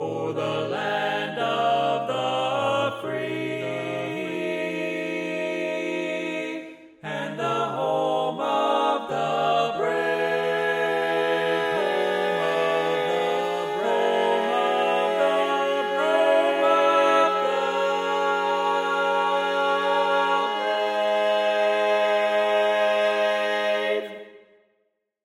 Key written in: A♭ Major
Type: Barbershop